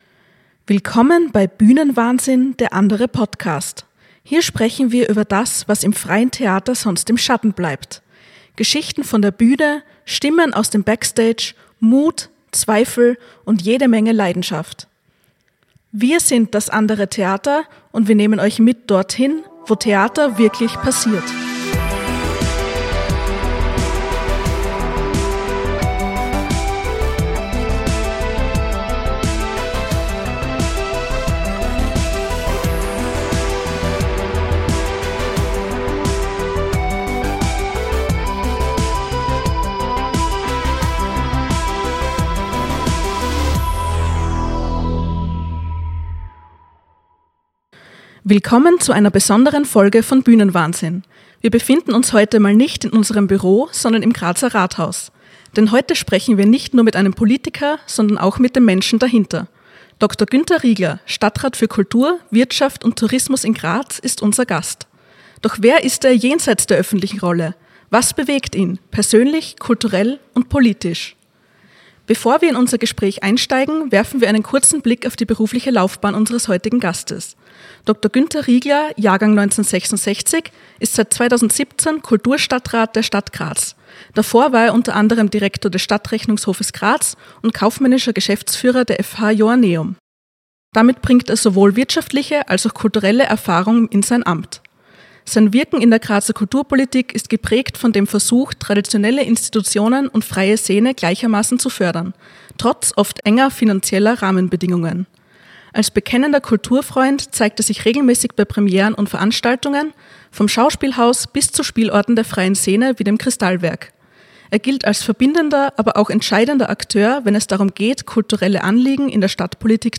In dieser besonderen Folge von Bühnenwahnsinn sprechen wir mit Günter Riegler, Kulturstadtrat der Stadt Graz.